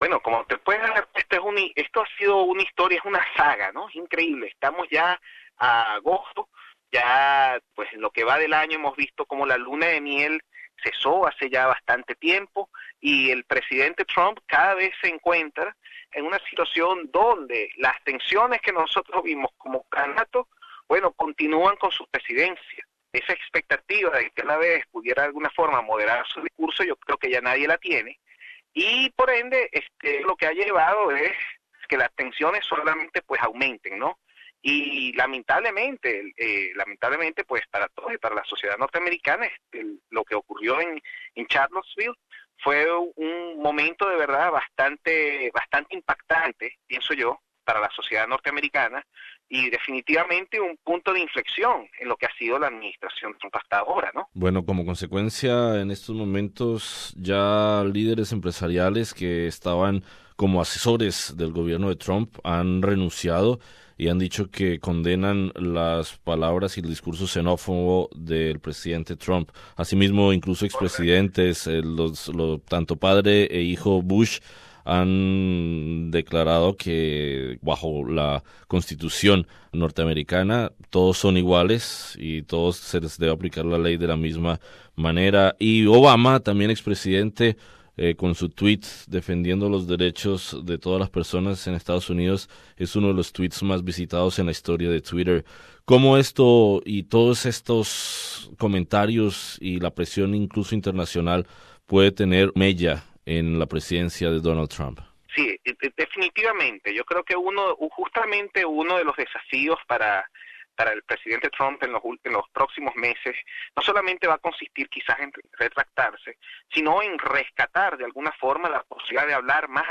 Entrevista con el analista de política internacional y académico en la Universidad de La Trobe en Melbourne